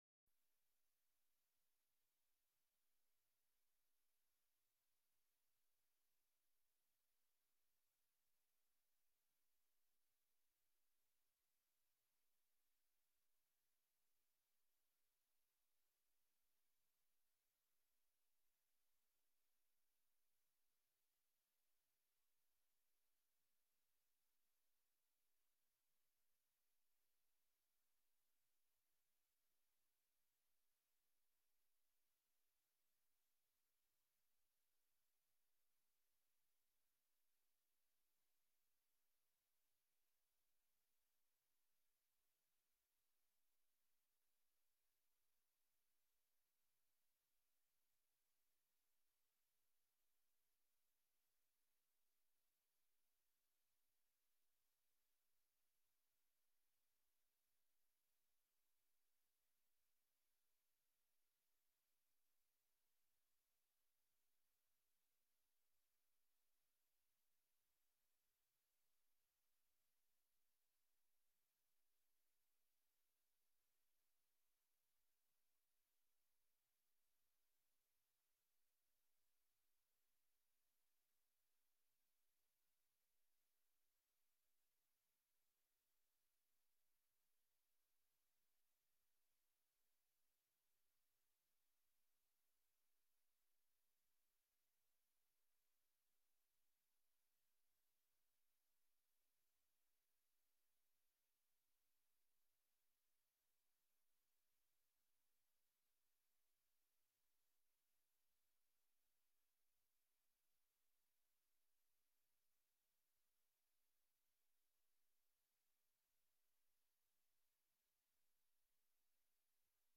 Download de volledige audio van deze vergadering
Locatie: Raadzaal